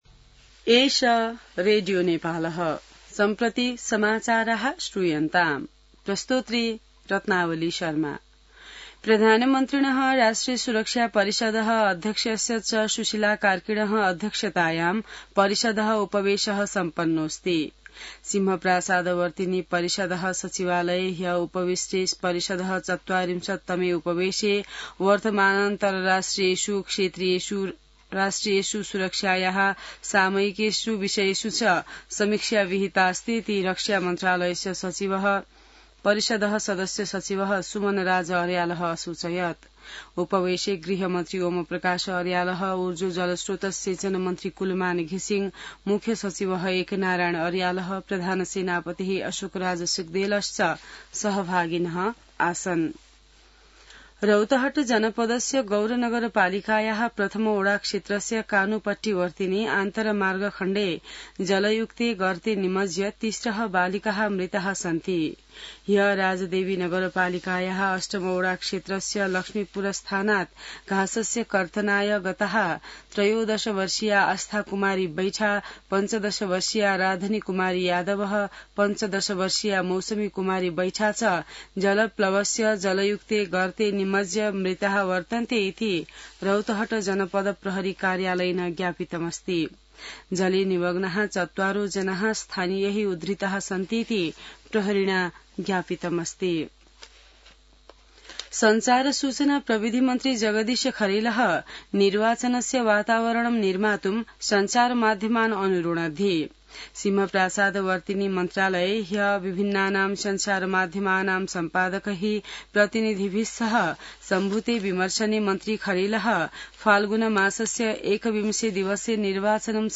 संस्कृत समाचार : २८ असोज , २०८२